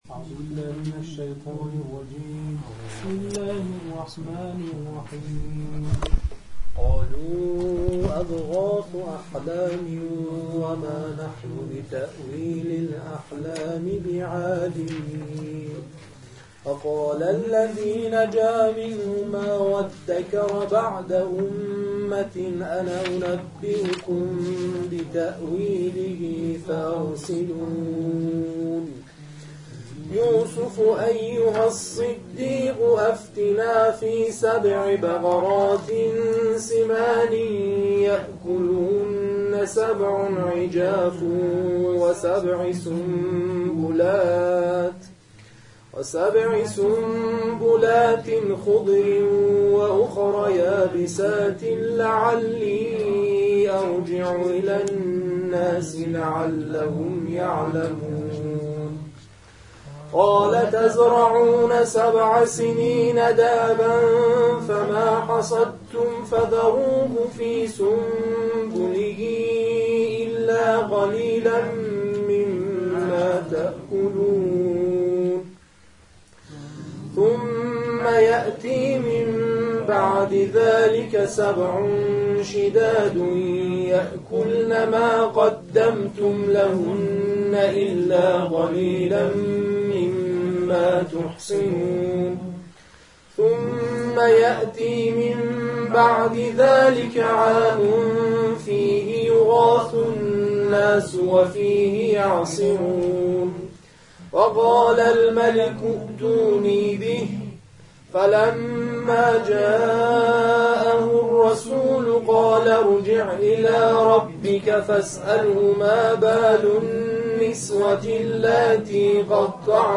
در ادامه جمع‌خوانی این جلسه ارائه می‌شود.